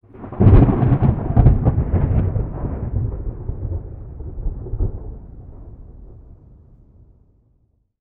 THUNDER_Clap_Rumble_01_stereo.wav